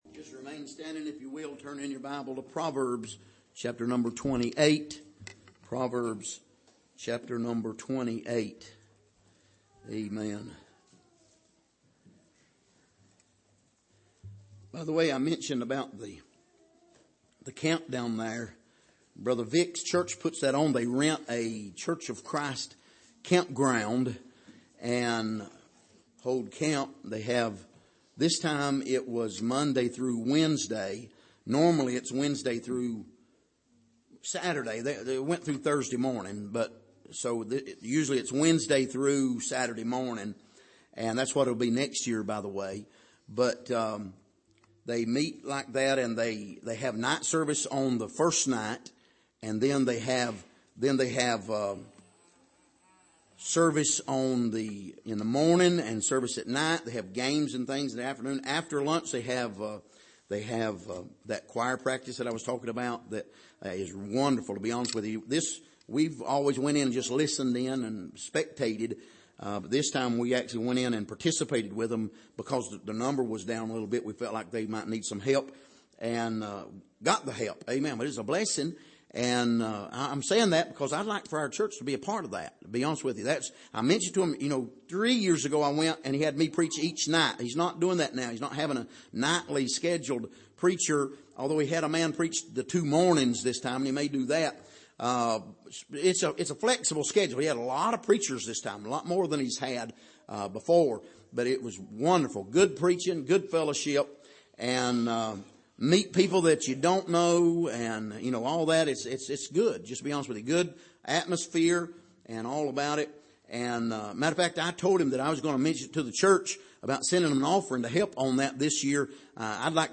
Passage: Proverbs 28:1-7 Service: Sunday Evening